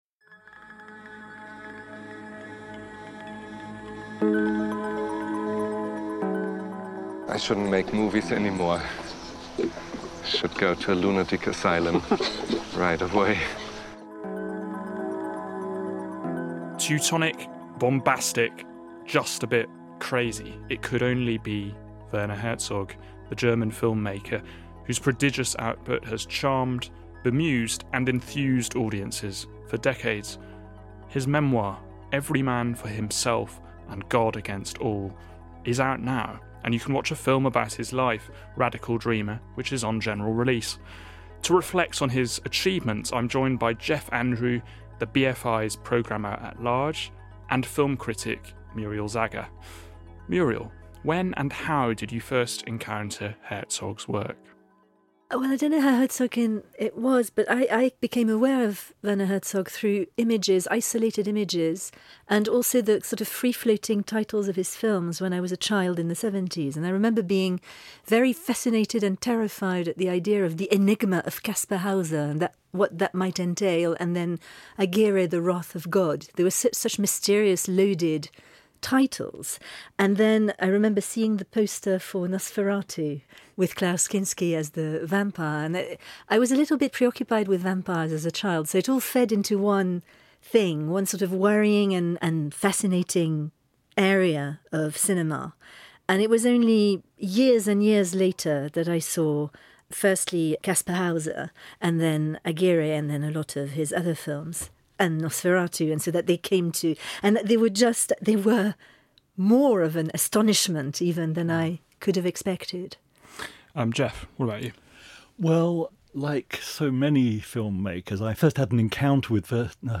Credits: The audio clips at 0:07 and 4:13 are taken from Werner Herzog: Radical Dreamer , directed by Thomas von Steinaecker.
The audio clip at 53:30 is an excerpt from The Enigma of Kaspar Hauser .